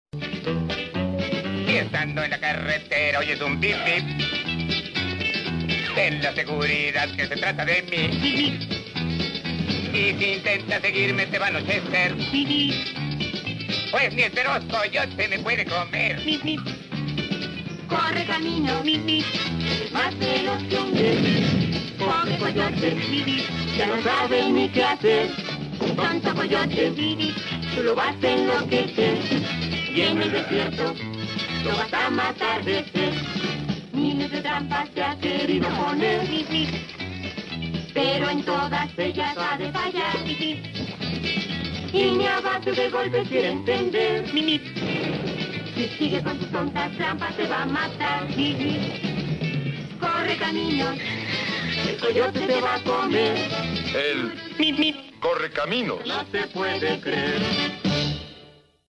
El Correcaminos Meep Meep!! sound effects free download